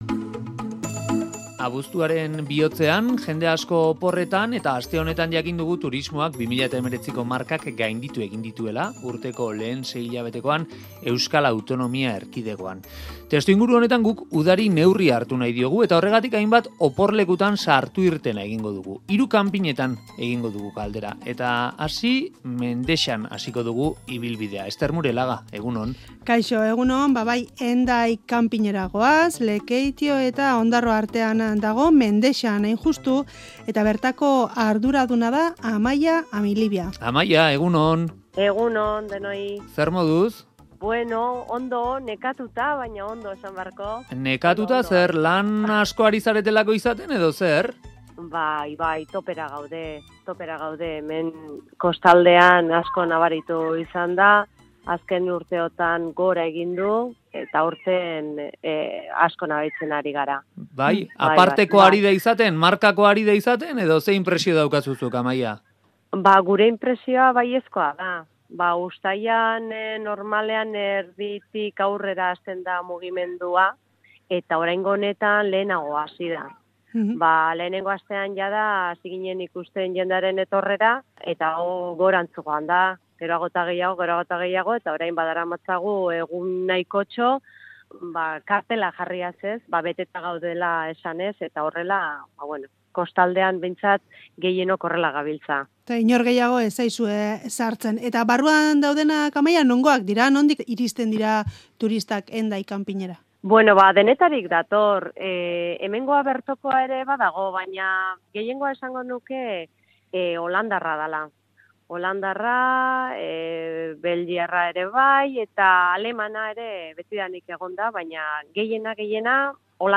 Audioa: Udari neurria hartu nahi izan diogu, eta horretarako Euskal Herriko hiru kanping-etara deitu dugu.